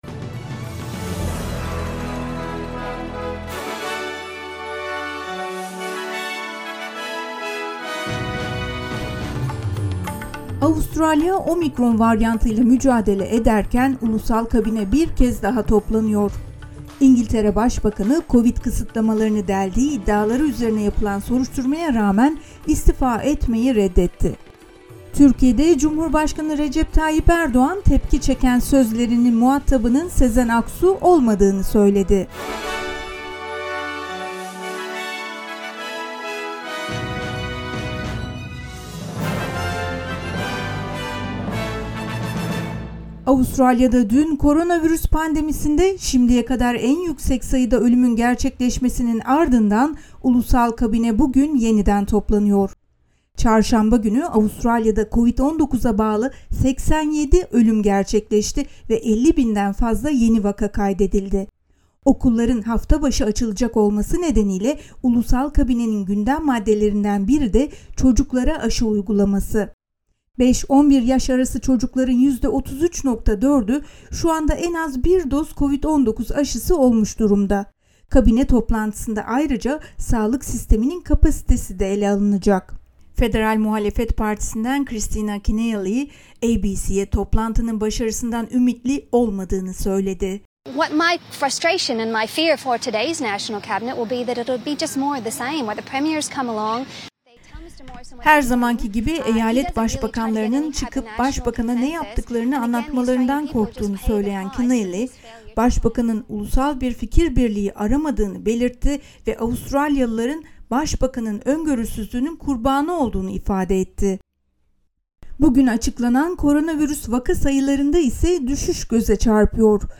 SBS Türkçe Haber Bülteni 27 Ocak